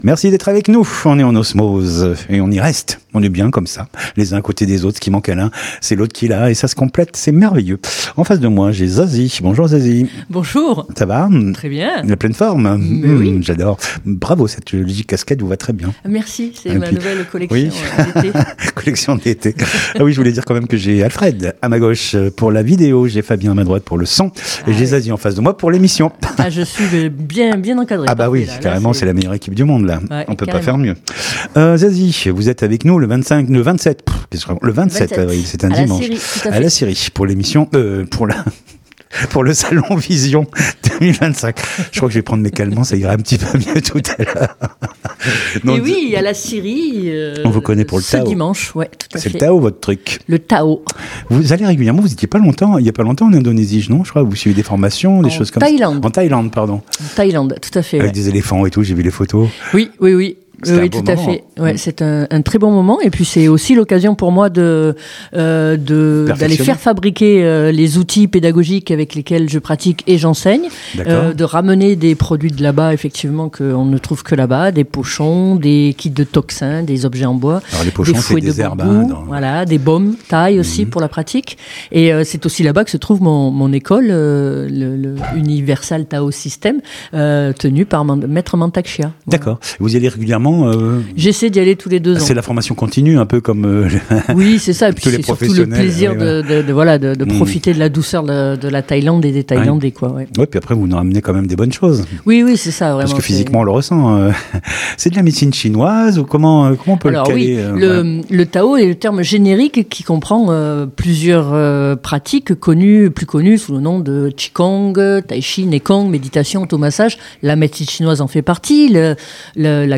Bien être/Santé Interviews courtes